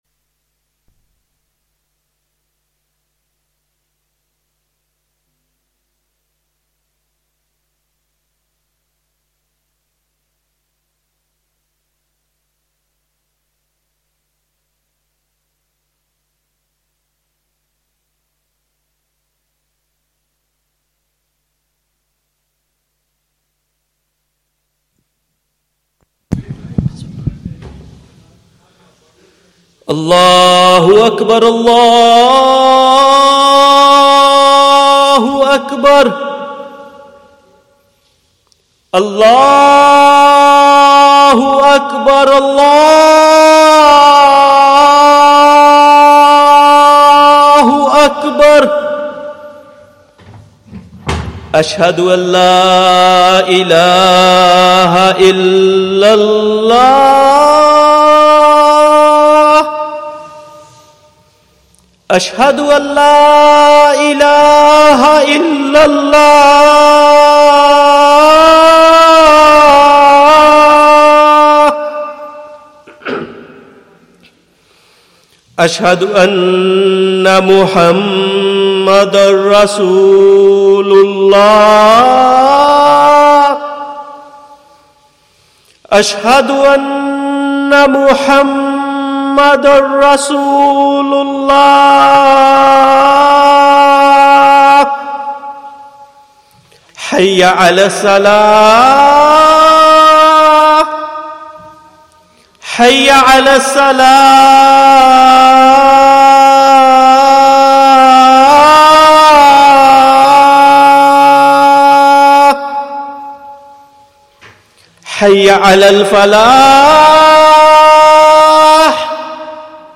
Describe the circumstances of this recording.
1st Annual Khanqahi Ijtema - Maghrib, Qiraat & Bayaan